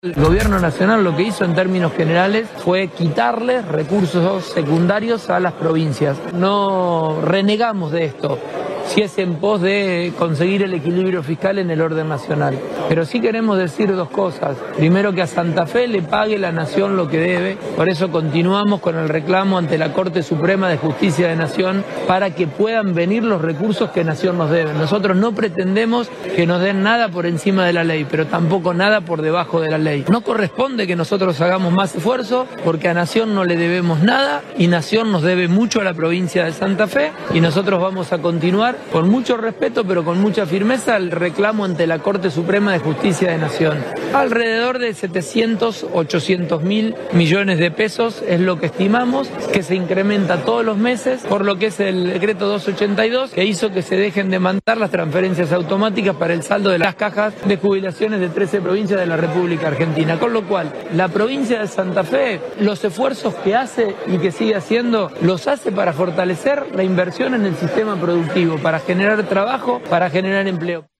MAXIMILIANO-PULLARO-Gobernador-de-Santa-Fe-.-No-le-debemos-nada-a-Nacion.mp3